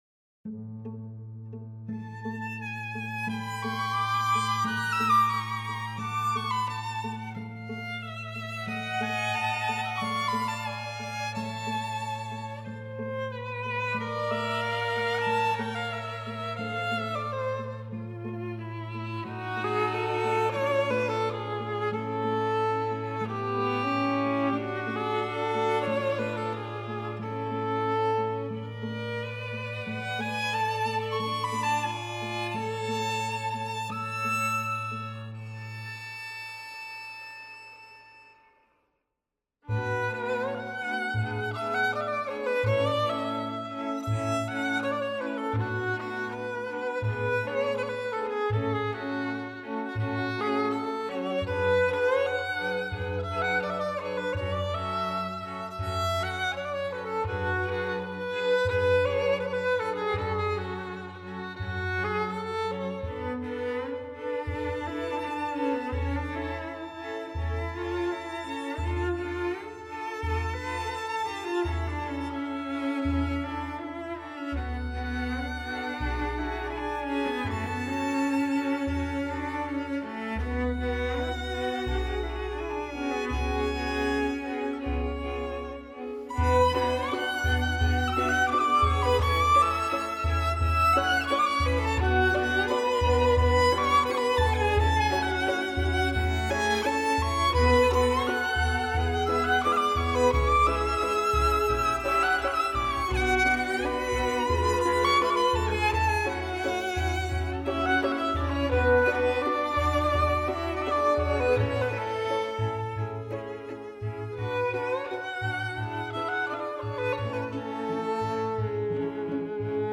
ORCHESTRA
POLOVTSIAN DANCES solo violin, string orchestra